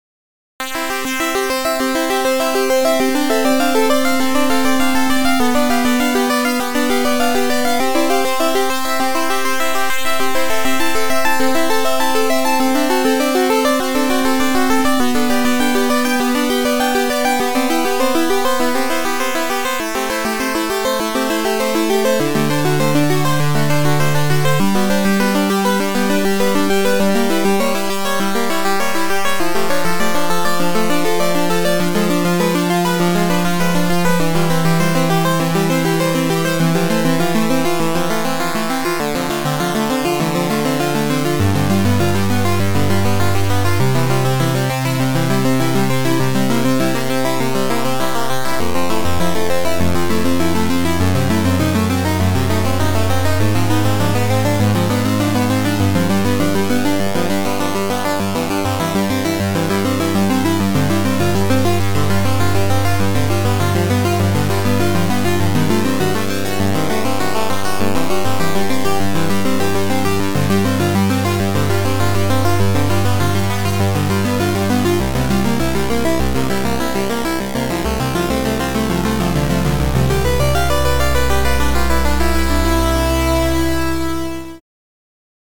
These songs are created with AutoCAD.